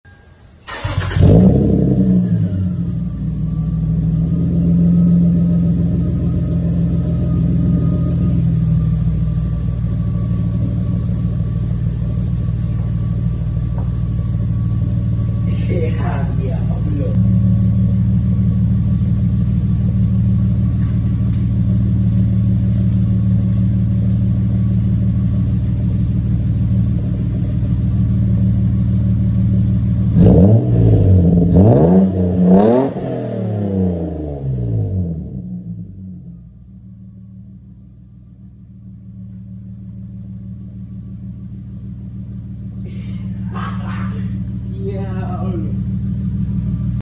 The new greddy exhaust for the IS sounds wild